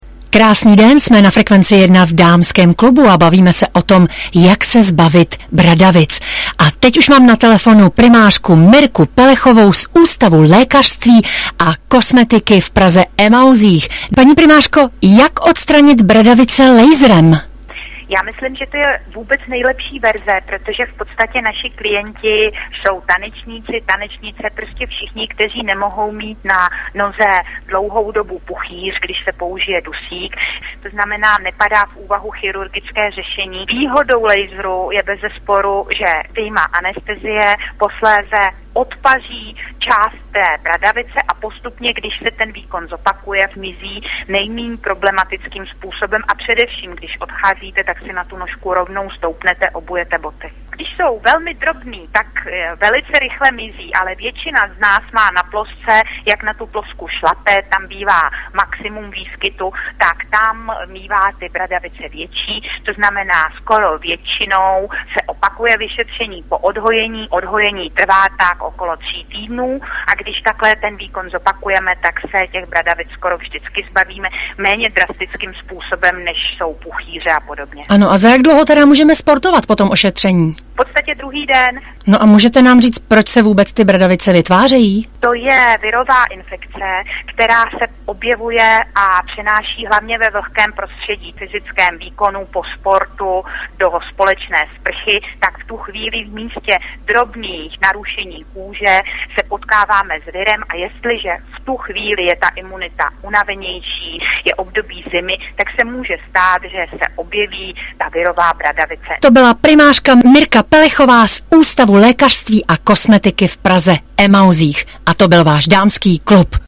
prostřednictvím přímých vstupů do vysílání.